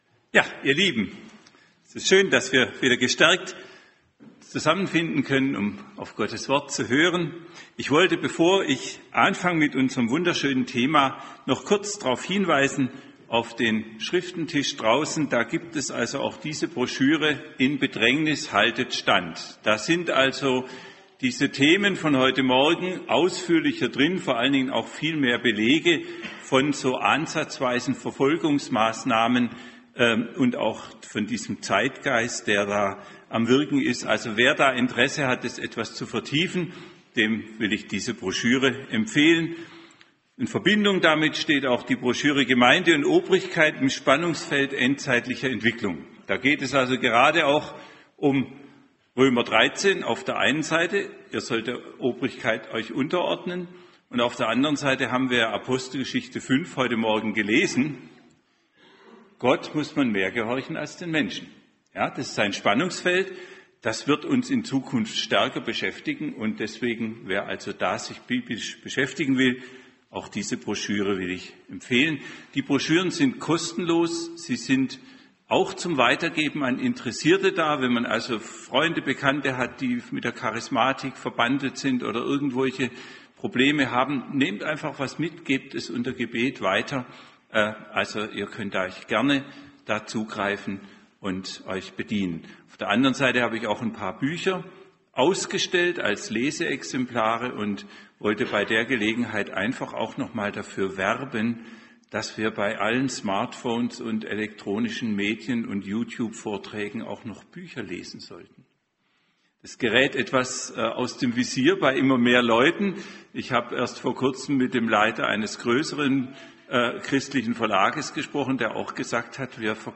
Diese ermutigende Predigt zeigt uns anhand einiger Verse aus Römer 8, daß es Gottes Absicht ist, uns Kindern Gottes, die wir Erben Seines herrlichen Reiches sind, in der jetzigen Zeit durch Leiden und Bedrängnisse für die kommende Herrlichkeit vorzubereiten.